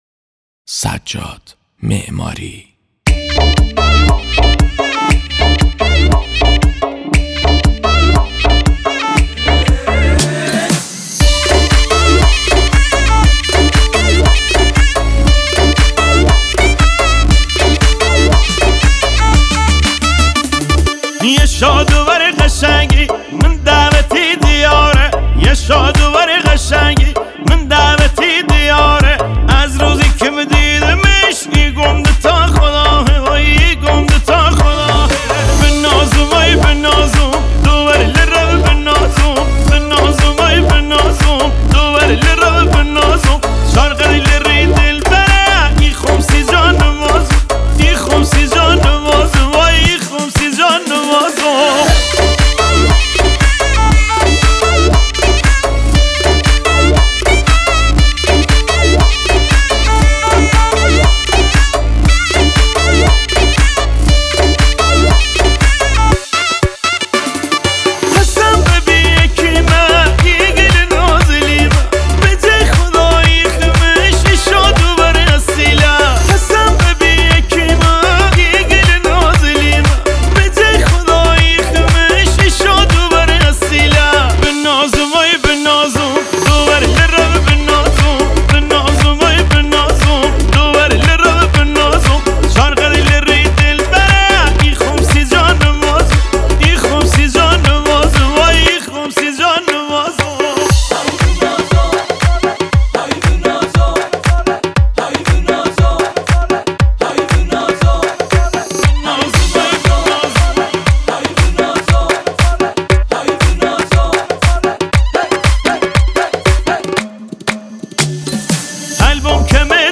Iranian music